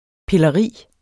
Udtale [ pelʌˈʁiˀ ]